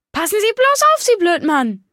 Datei:Maleuniquebiwwy dialoguecrimekarma hello 0007d4d2.ogg
Fallout 3: Audiodialoge